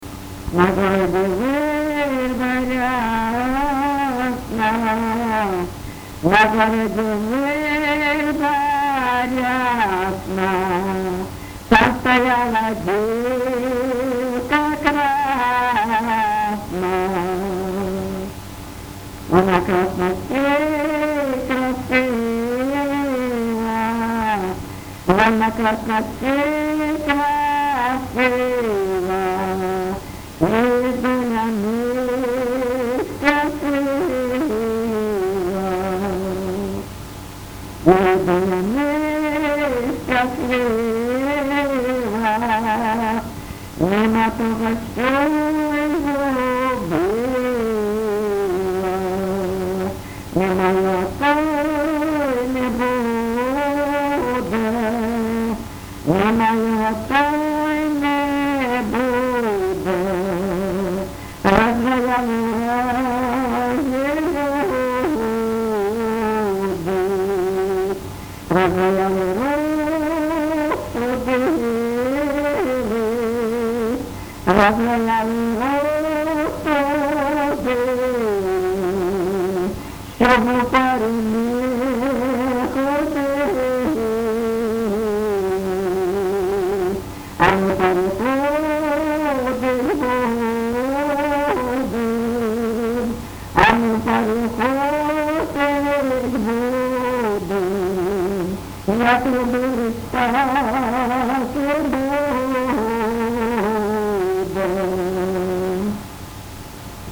ЖанрПісні з особистого та родинного життя
Місце записус. Привілля, Словʼянський (Краматорський) район, Донецька обл., Україна, Слобожанщина